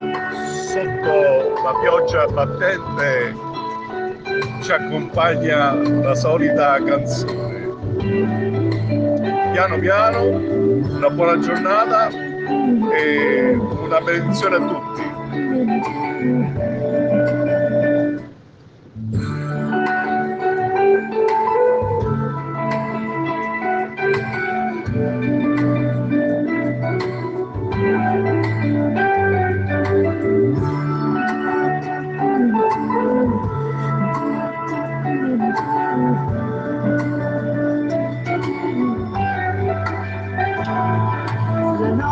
Cinguettio del Pettirosso